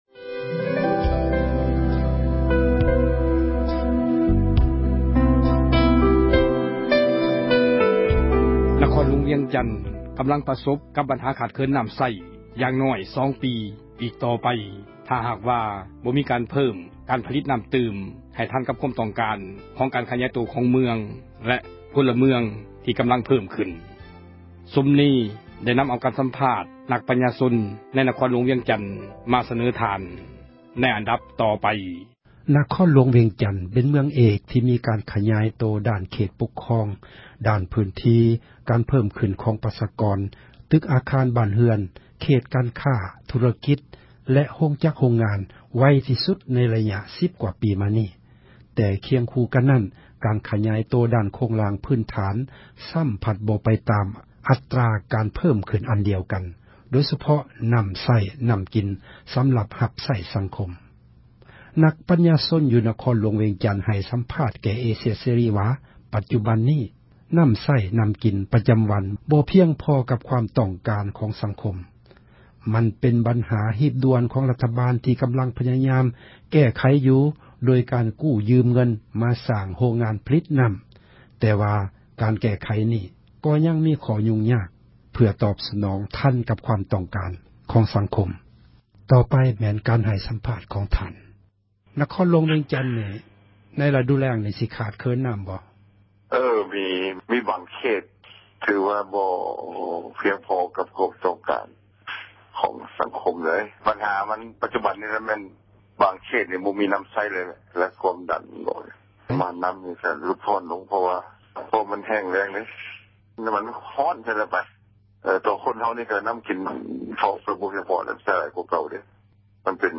ສັມພາດ ນັກ ປັນຍາຊົນ ໃນ ນະຄອນຫລວງ ວຽງຈັນ...